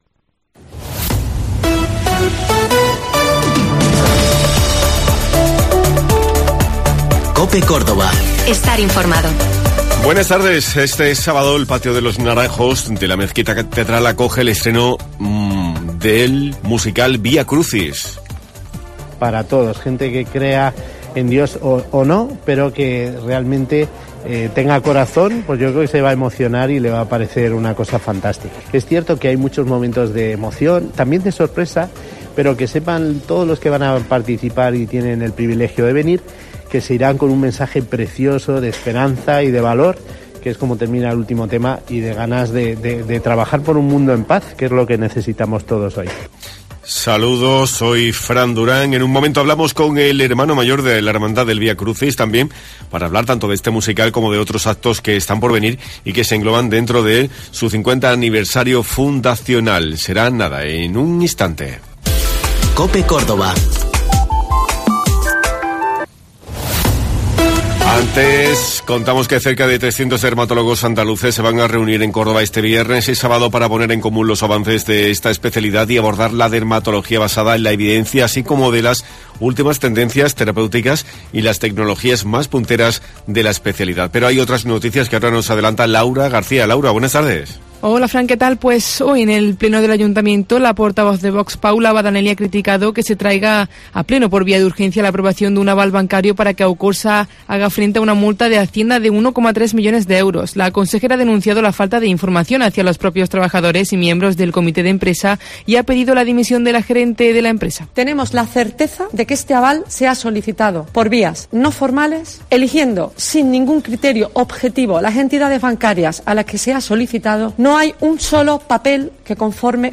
Un repaso diario a la actualidad y a los temas que te preocupan.